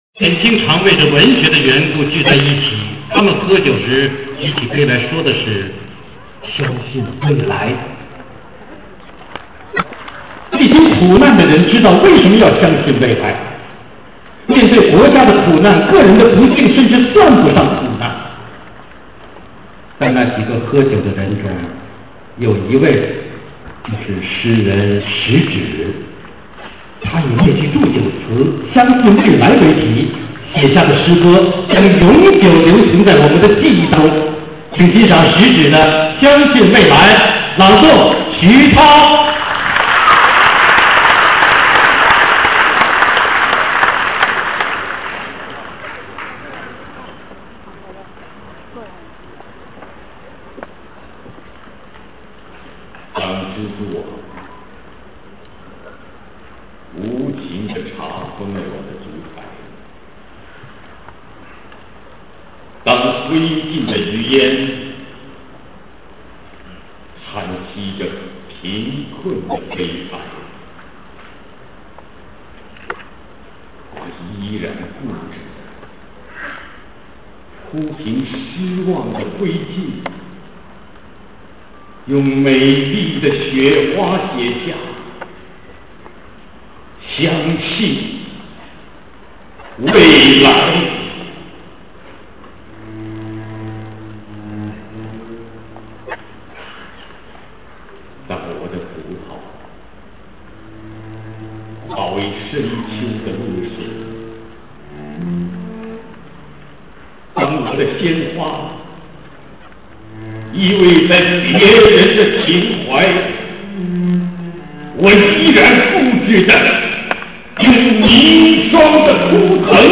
2009-05-31 19:37 平板 | 收藏 | 小 中 大 [31/5/2009]《相信未来[食指]》朗诵：徐涛(现场录音) 上传的是现场录音，效果不是很好，可是非常真实。